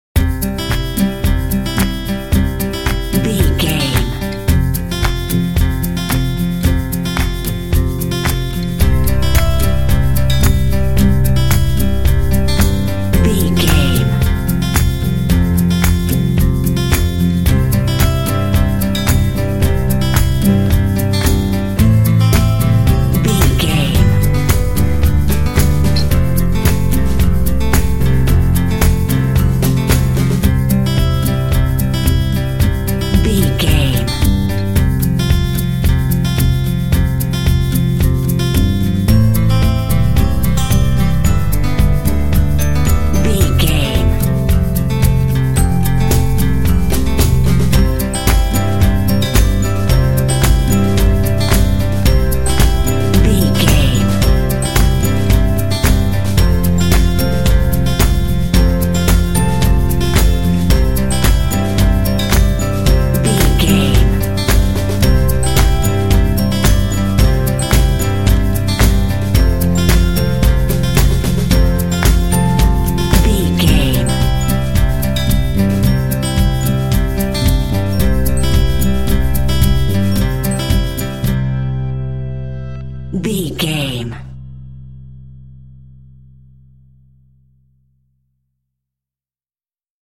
Uplifting
Ionian/Major
cheerful/happy
joyful
acoustic guitar
bass guitar
drums
percussion
electric piano
indie
pop
contemporary underscore